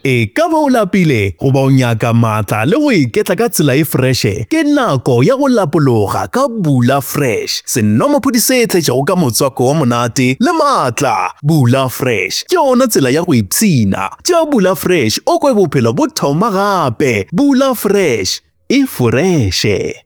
commercial, conversational, energetic, friendly, promo, soothing
My demo reels